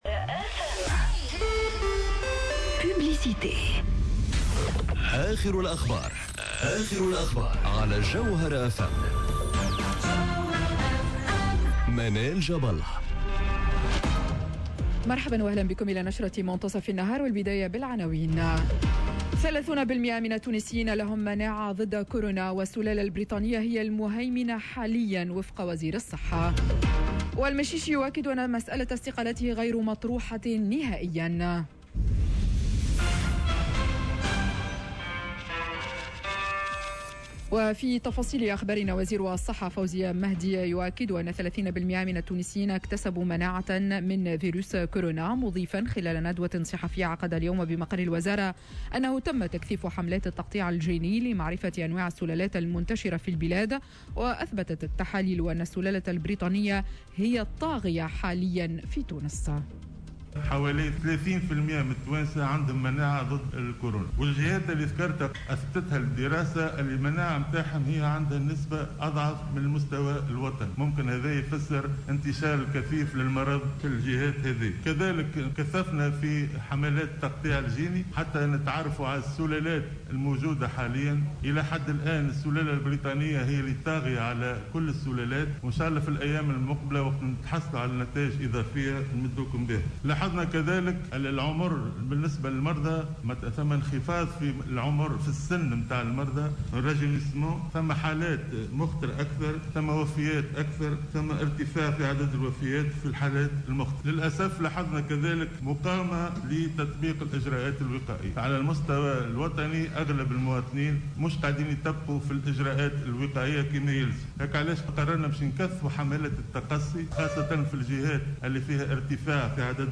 نشرة أخبار منتصف النهار ليوم الثلاثاء 22 جوان 2021